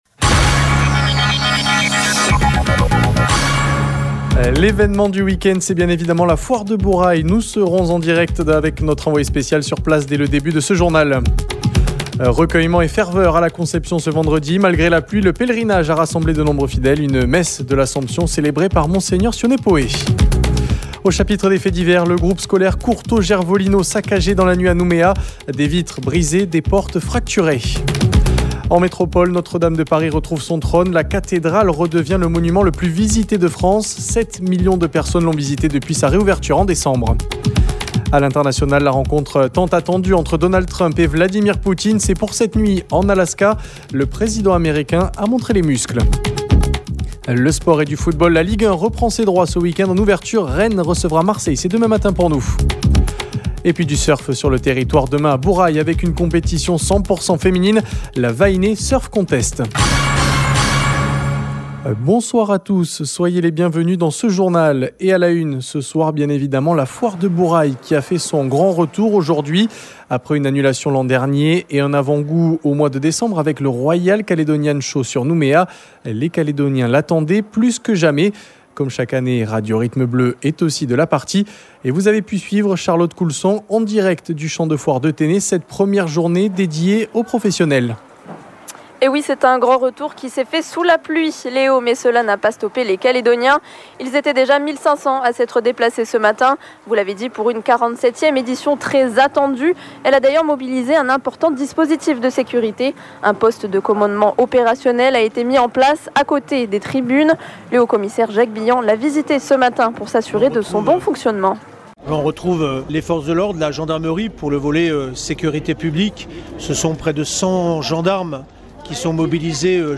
L’évènement du week-end, c’est bien évidemment la Foire de Bourail : nous serons en direct avec notre envoyée spéciale sur place.